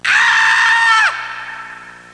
Scream Sound Effect
Download a high-quality scream sound effect.
scream-9.mp3